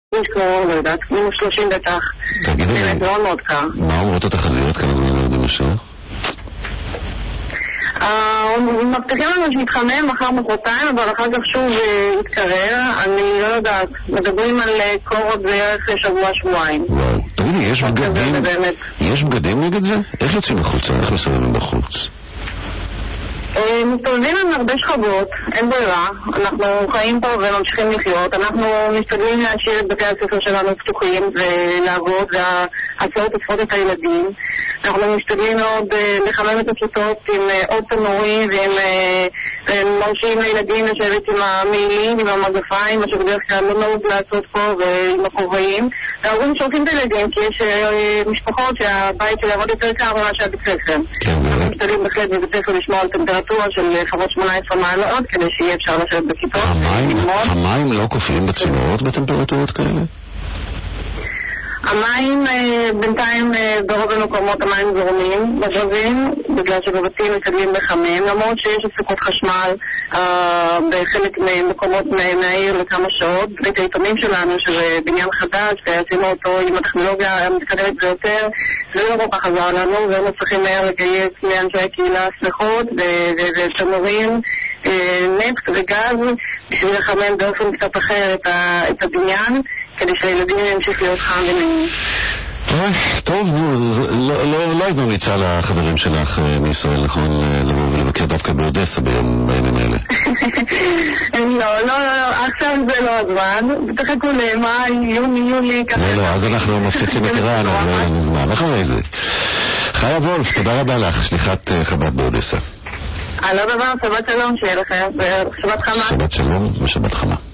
תמליל הראיון מ'גלי-צה"ל':